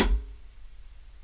HIHAT TOP.wav